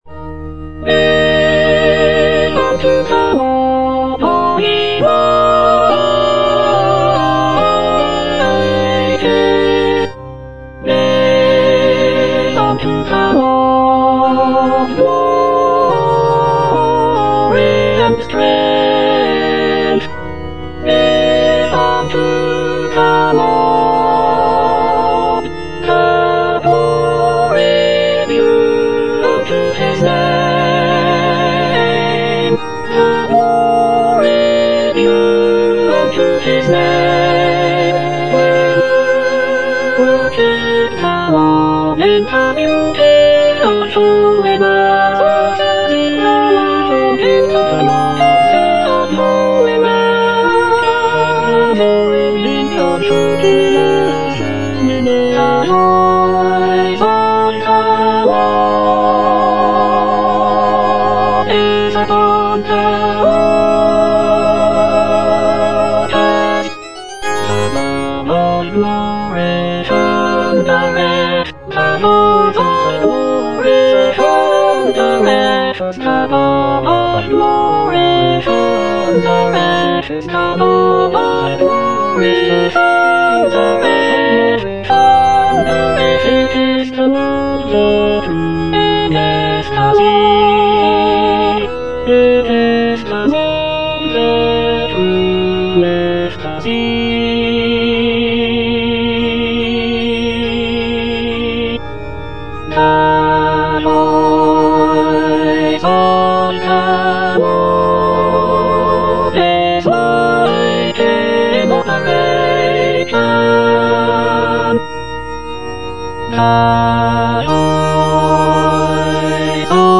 E. ELGAR - GIVE UNTO THE LORD Soprano II (Emphasised voice and other voices) Ads stop: auto-stop
"Give unto the Lord" is a sacred choral work composed by Edward Elgar in 1914.